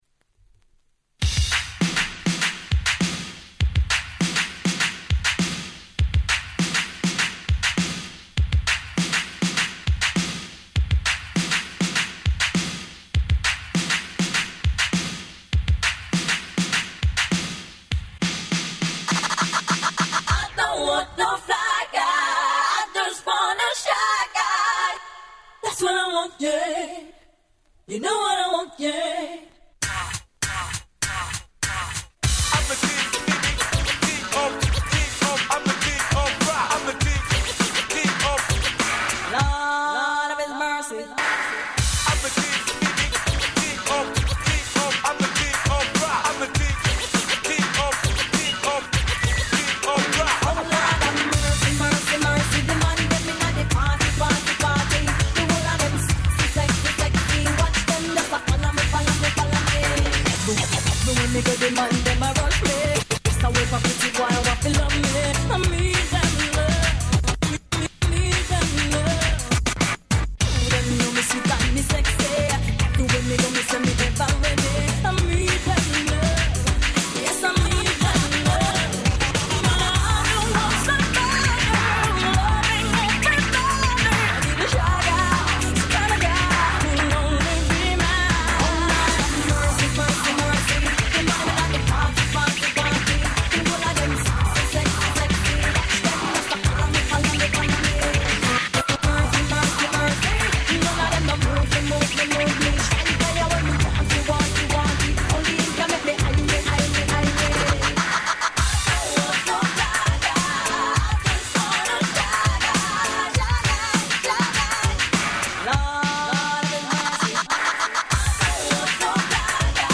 アカペラで抜ける所なんてもう鳥肌物です！！